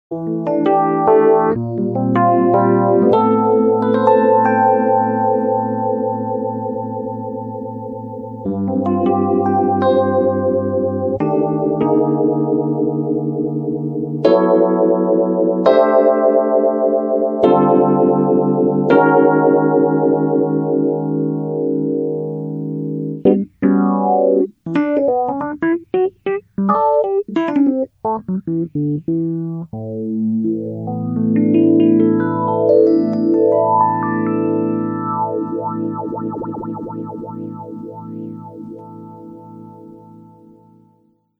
EH SmallStone PhaserEHX SmallStone Phaser, OTA/VCA-gesteuerte Filter, 4 Stufen, spätere Version.
smallstone.mp3